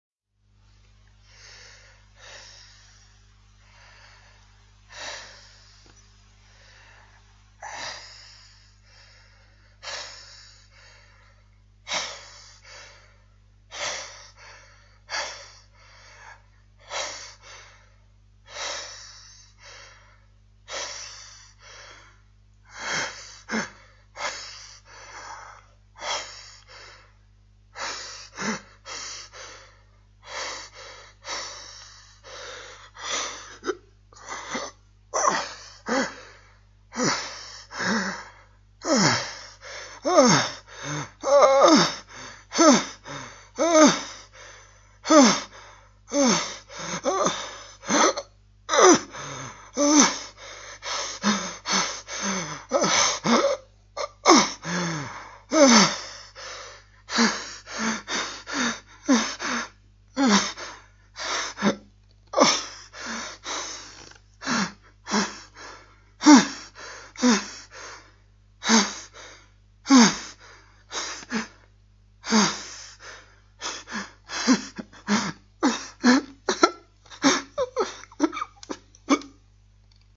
male pain grunt
描述：male painful grunt
标签： anguish grunt male pain painful
声道立体声